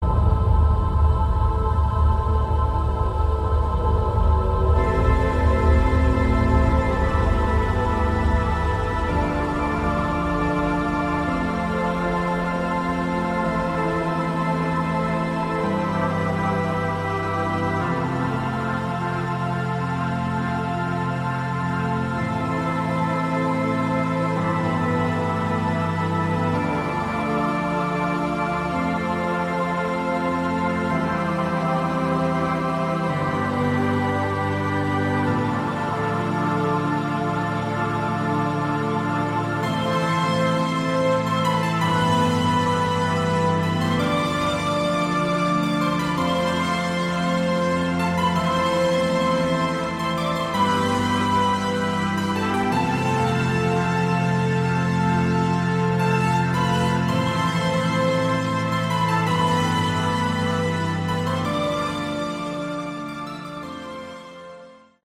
Category: Melodic Rock
synthesizers, vocals
lead vocals, guitar, bass